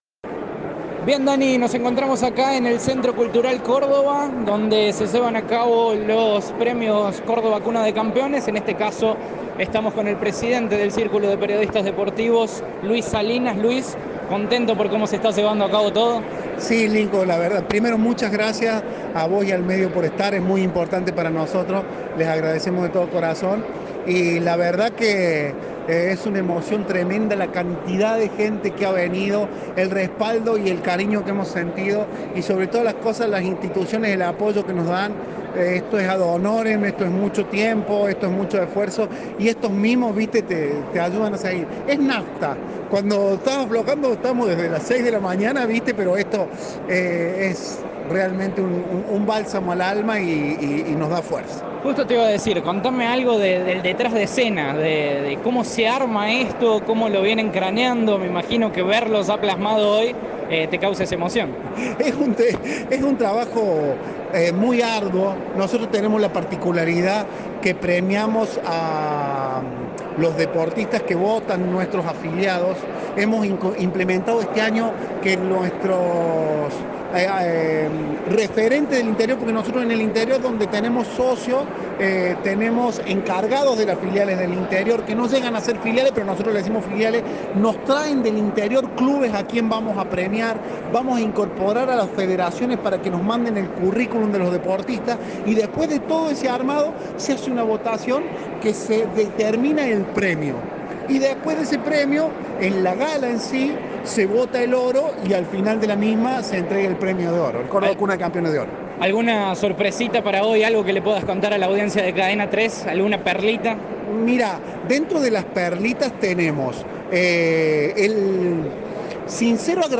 dialogó con Cadena 3
En el Centro Cultural Córdoba se llevó a cabo la 43° edición de los premios "Córdoba, cuna de campeones", organizada por el Círculo de Periodistas Deportivos.